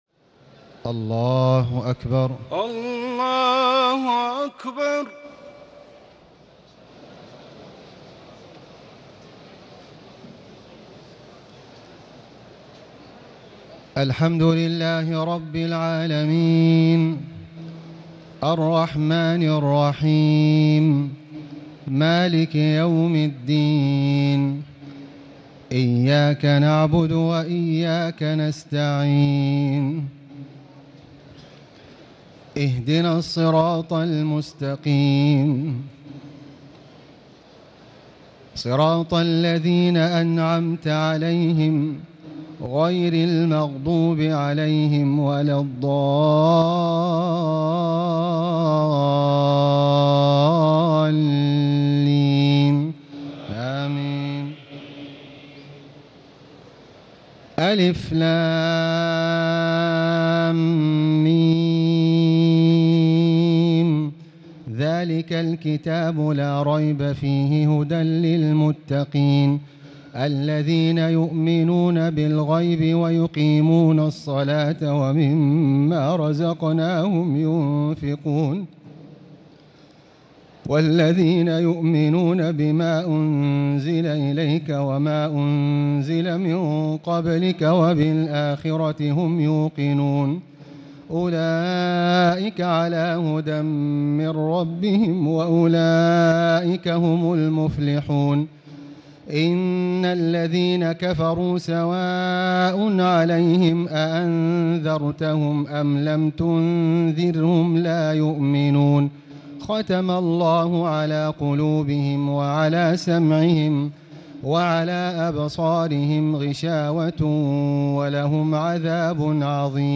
تهجد ليلة 21 رمضان 1439هـ من سورة البقرة (1-91) Tahajjud 21 st night Ramadan 1439H from Surah Al-Baqara > تراويح الحرم المكي عام 1439 🕋 > التراويح - تلاوات الحرمين